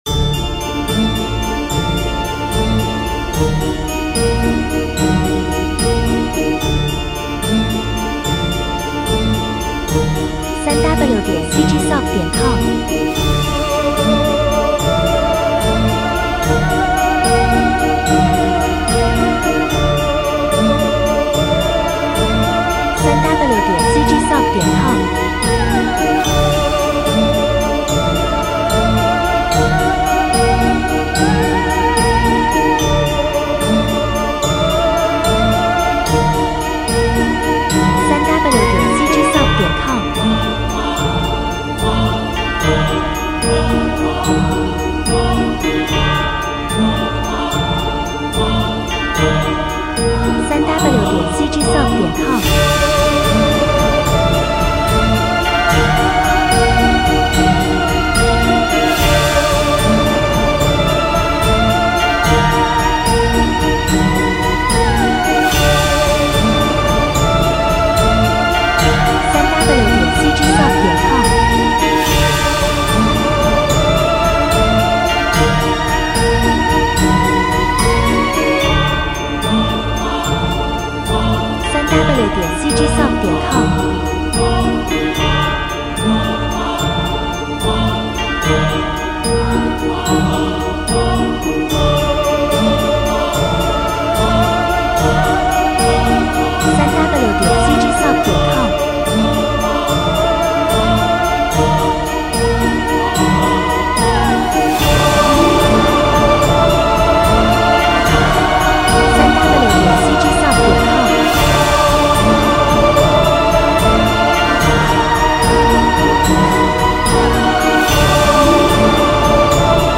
16-Bit Stereo
克里思:110 BPM
公墓 卡通 喜剧 广告 德古拉 恐惧 愤怒 游戏 电影 蝙蝠 邪恶 黑暗